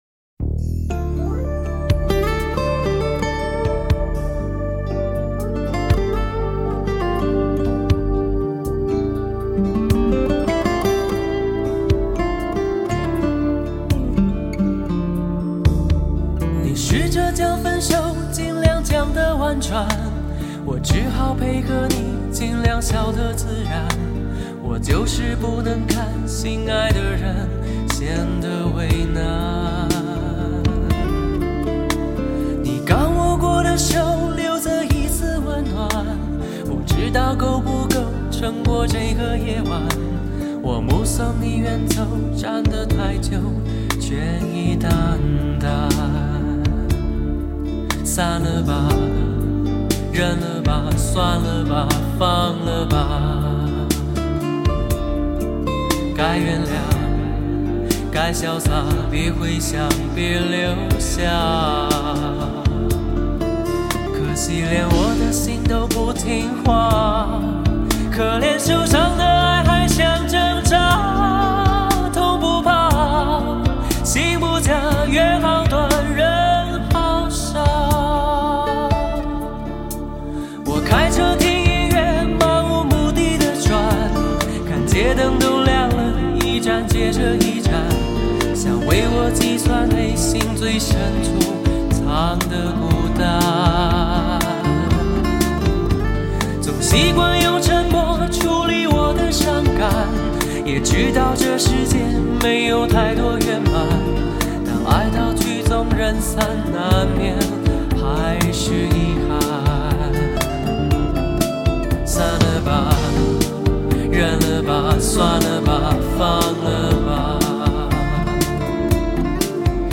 唱片中歌者的高音完全不给人压力。